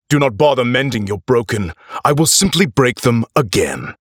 Teenager, Young Adult, Adult
Has Own Studio